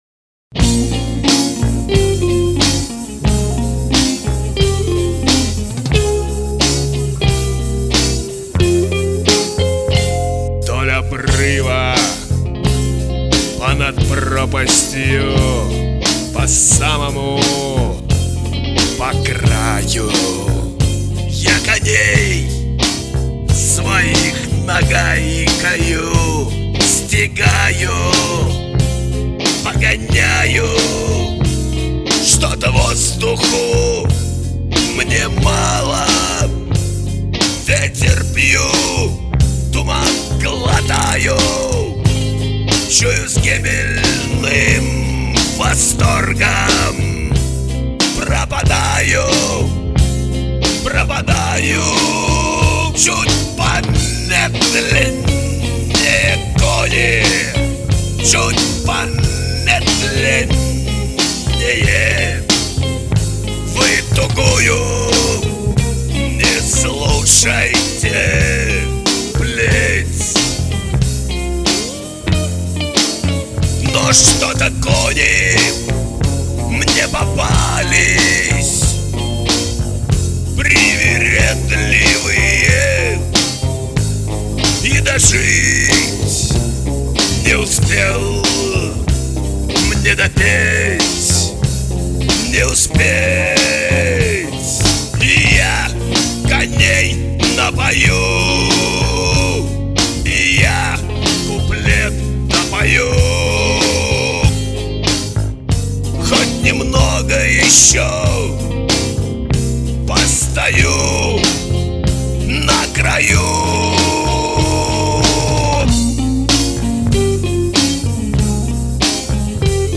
вокал, гитара
бас-гитара
drums